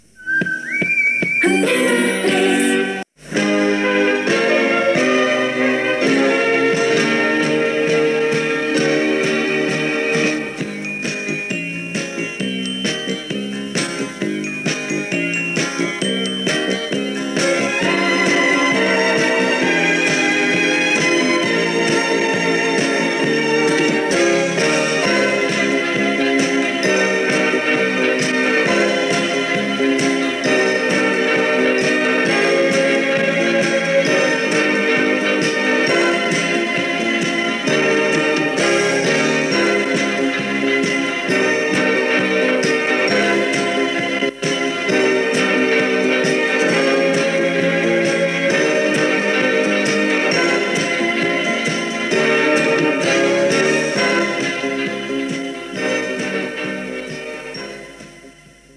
Indicatiu i sintonia instrumental de l'emissora.